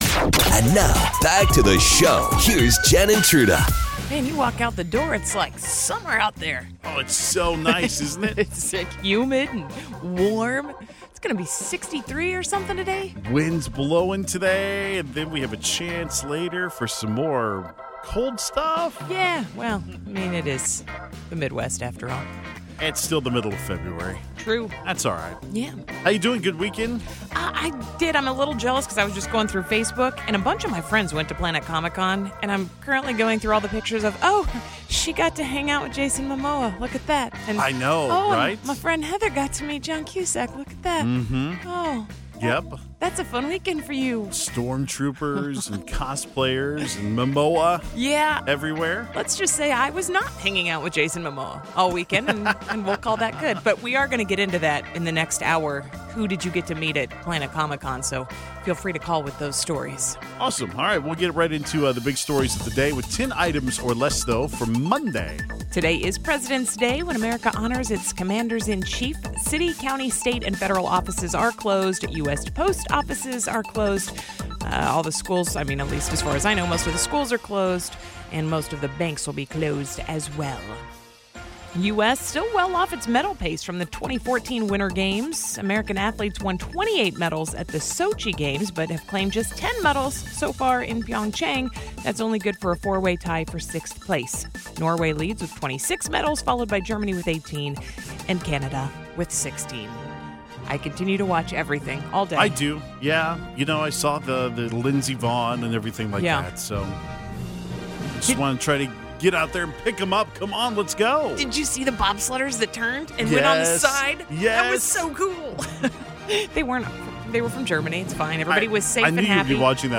We asked you: Who did you meet at ComicCon this weekend? It was great to hear from all of the fluttering hearts and tongue-tied fans. Fergie sang a very sultry, sexy, downright strange version of the Star Spangled Banner during the weekend's NBA All-Star game. We play it for you -- multiple times!